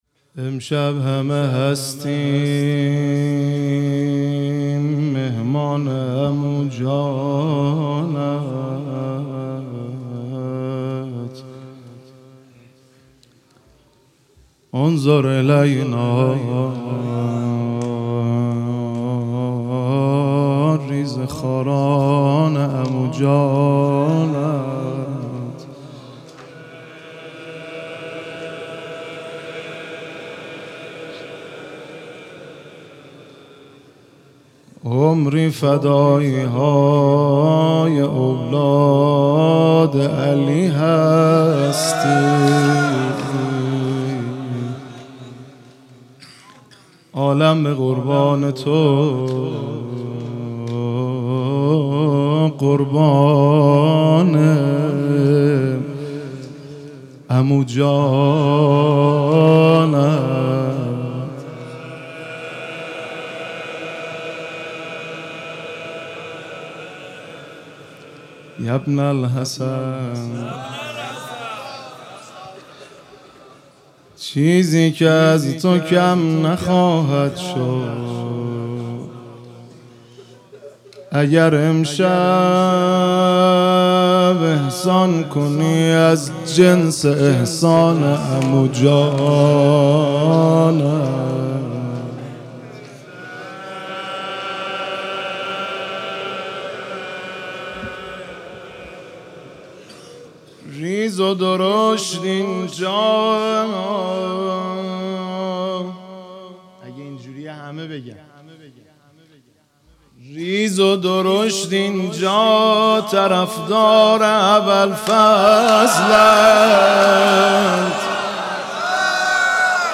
مراسم عزاداری شب نهم محرم الحرام با مداحی مهدی رسولی در هیئت ثارالله شهر زنجان برگزار شد.
روضه زمینه واحد شور